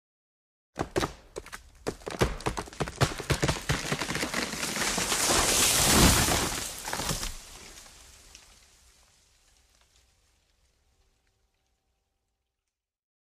دانلود آهنگ افتادن درخت 1 از افکت صوتی طبیعت و محیط
جلوه های صوتی
دانلود صدای افتادن درخت 1 از ساعد نیوز با لینک مستقیم و کیفیت بالا